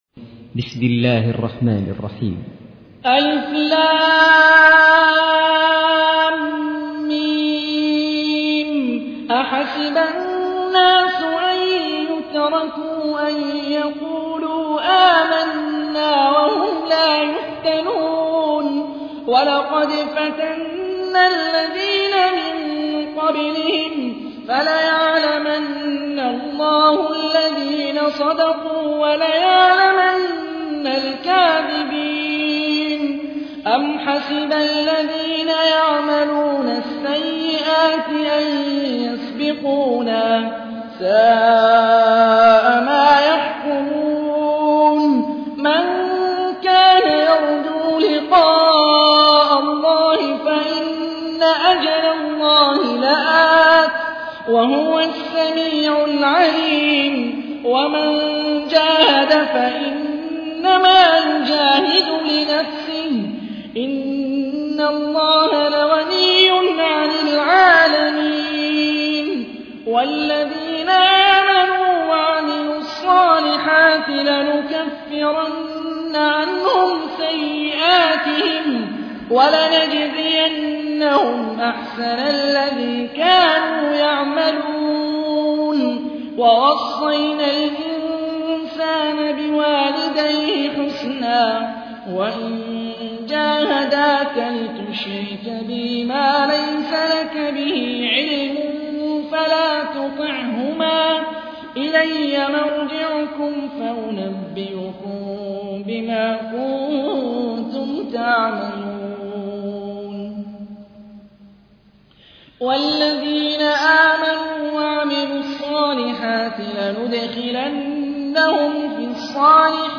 تحميل : 29. سورة العنكبوت / القارئ هاني الرفاعي / القرآن الكريم / موقع يا حسين